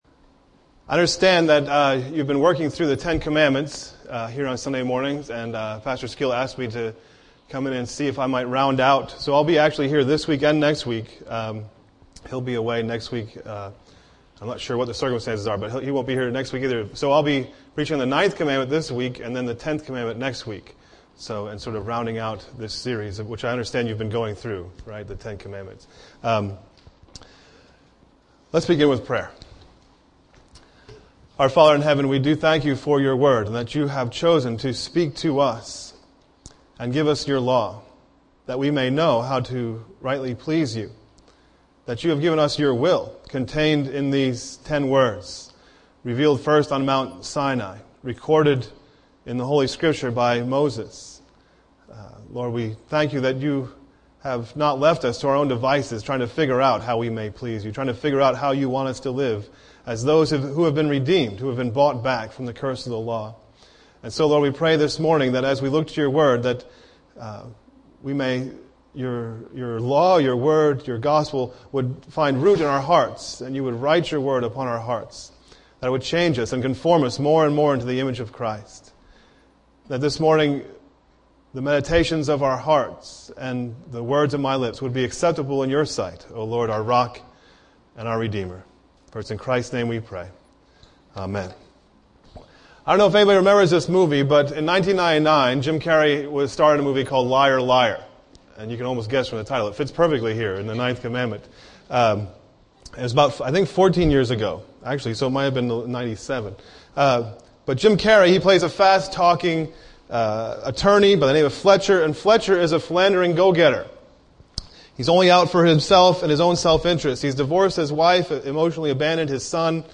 A message from the series "10 Commandments."